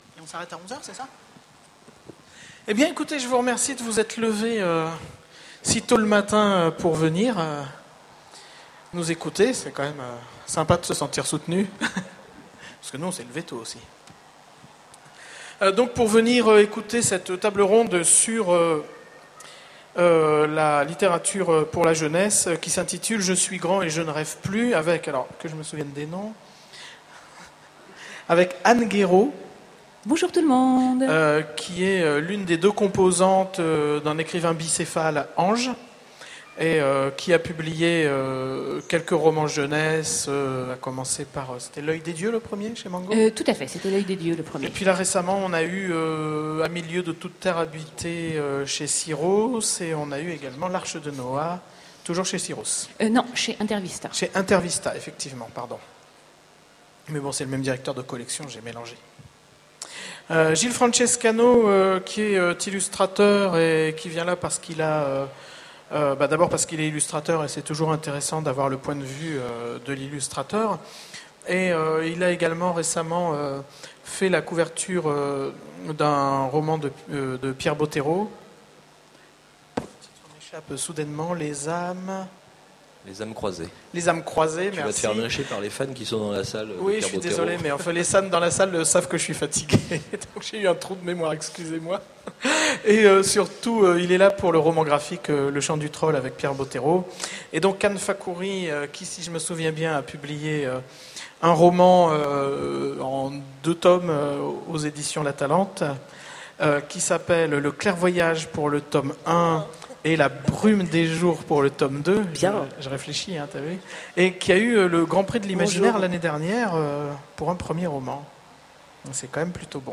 Voici l'enregistrement de la conférence " Je suis grand et je ne rêve plus ! " aux Utopiales 2010. Pourquoi les enfants s’arrêtent-ils un jour de dessiner ? Qu’en est-il de notre capacité à rêver, à nous évader ?